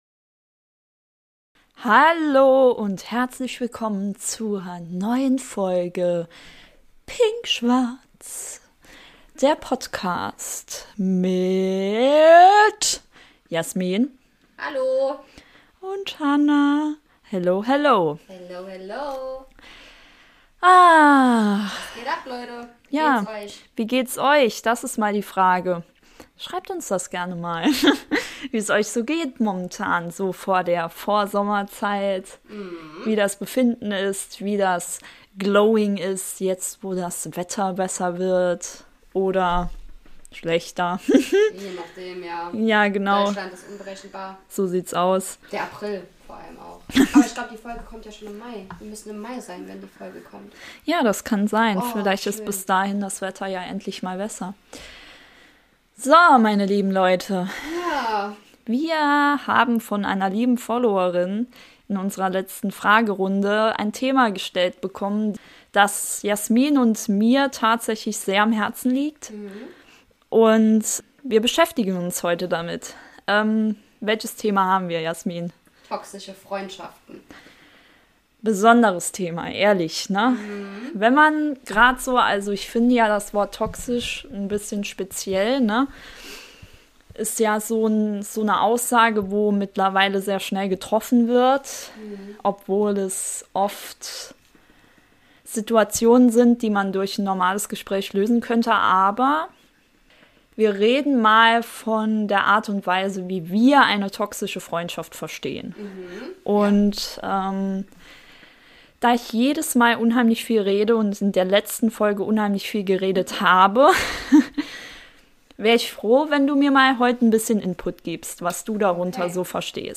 Die Podcasterinnen